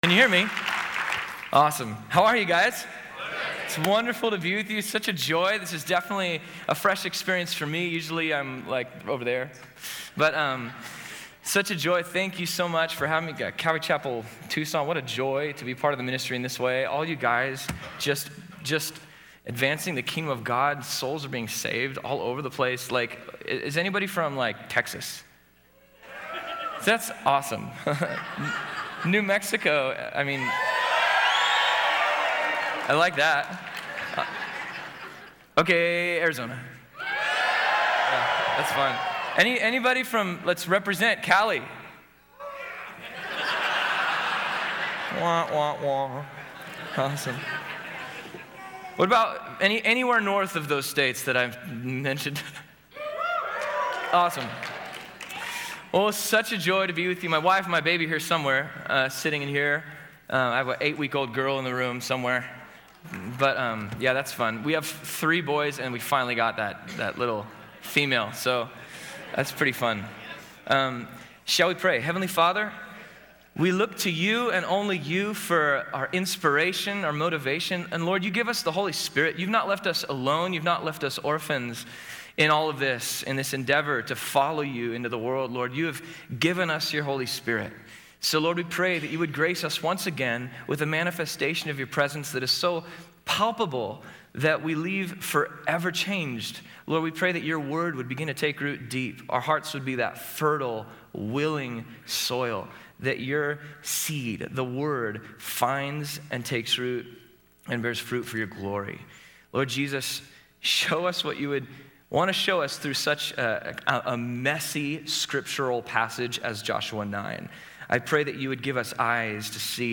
Southwest Pastors and Leaders Conference 2013